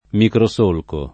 microsolco [ mikro S1 lko ] s. m.; pl. ‑chi